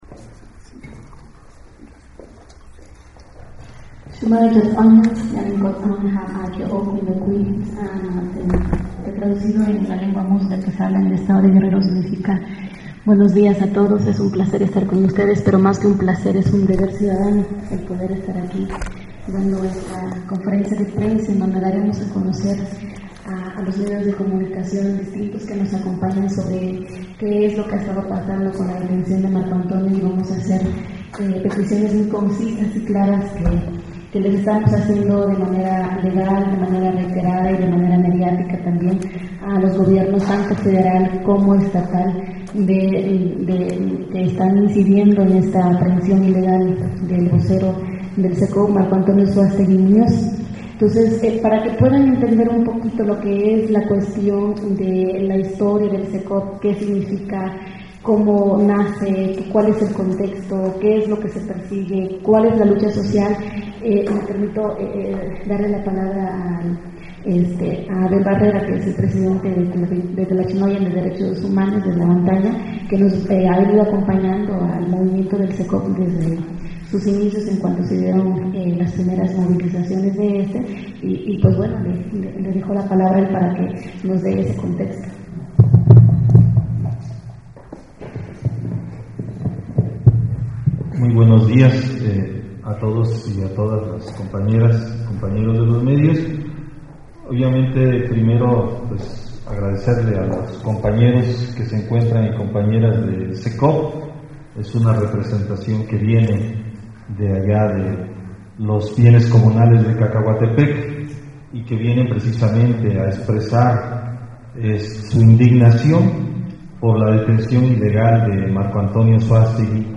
Mitin y conferencia de prensa
conferenciacecop.mp3